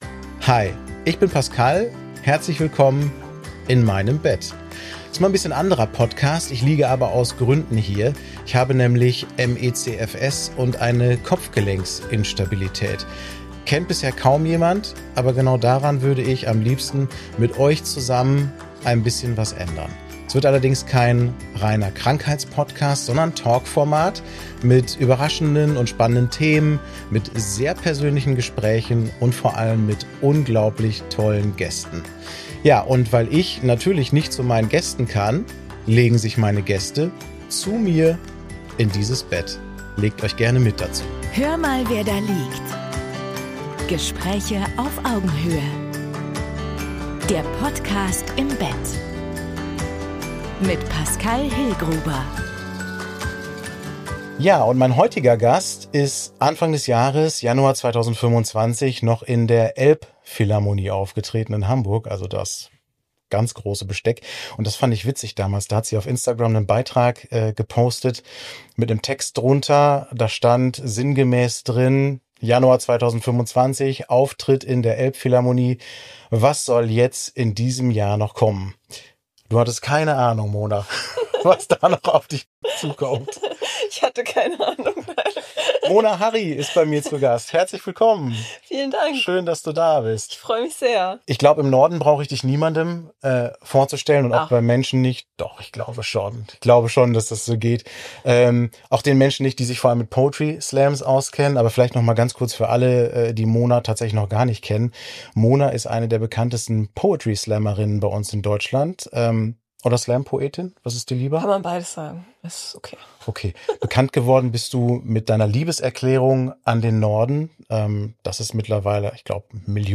Ein Gespräch über Sprache, Selbstfindung, Sichtbarkeit und den Wunsch, nicht nur „funktionieren“ zu müssen.